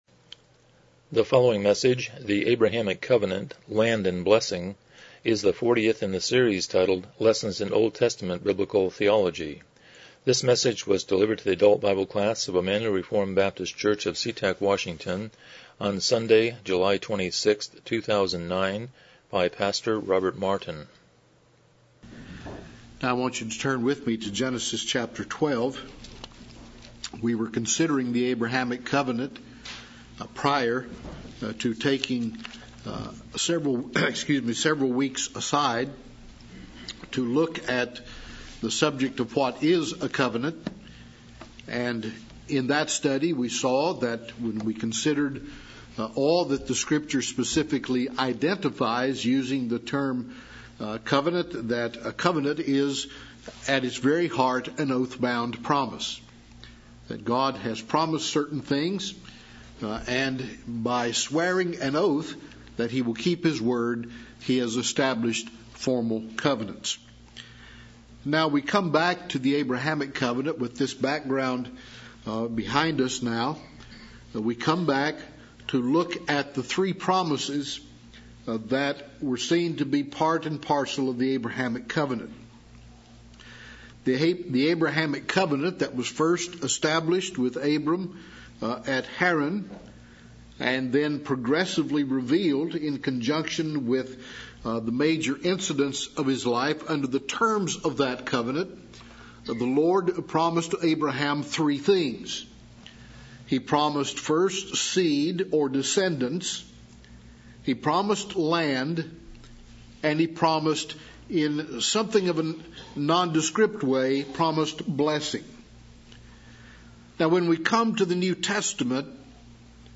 Series: Lessons in OT Biblical Theology Service Type: Sunday School « 68 Chapter 11.5